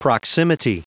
Prononciation du mot proximity en anglais (fichier audio)
Prononciation du mot : proximity